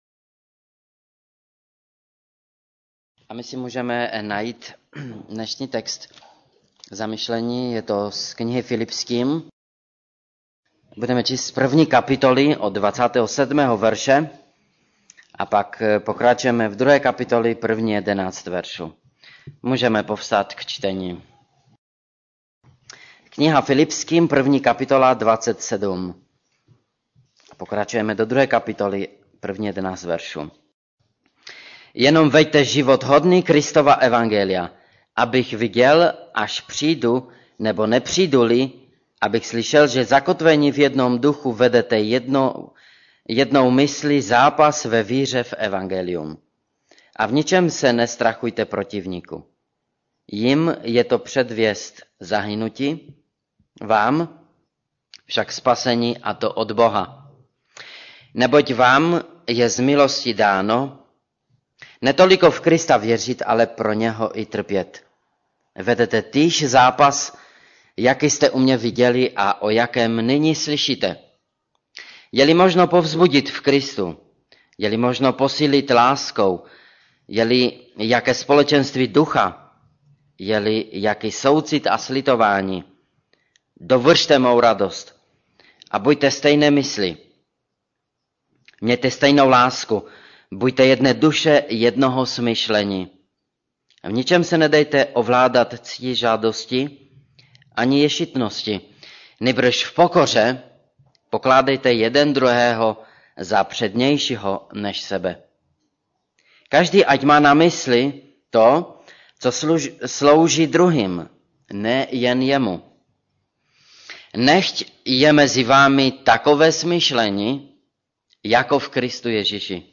Radostný postoj Kategorie: Kázání MP3 Zobrazení: 3435 Jak žít radostný život - Fil 1:27-2:11 3.